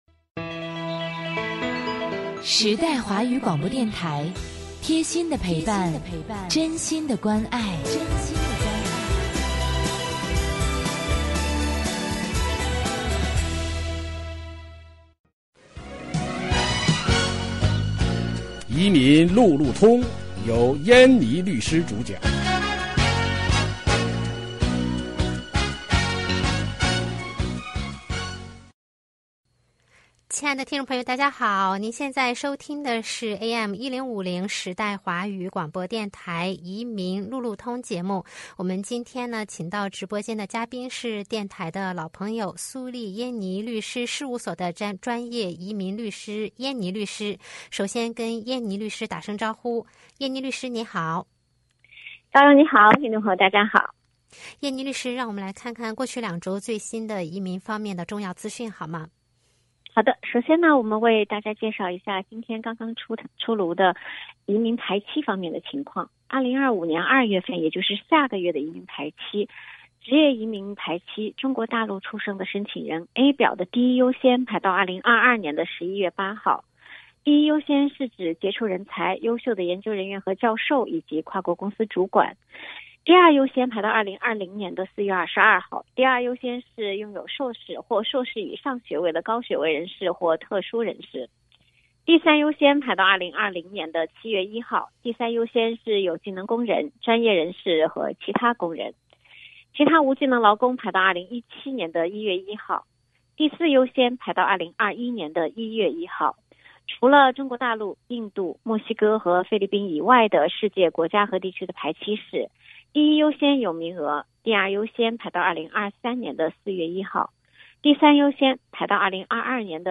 每月第二、第四个周一下午5点30分，AM1050时代华语广播电台现场直播，欢迎听众互动。